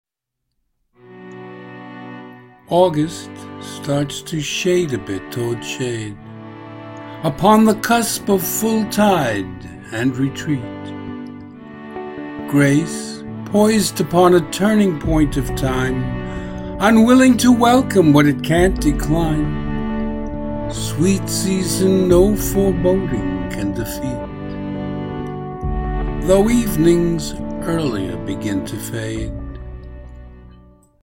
Music: Goldberg Variations: Aria.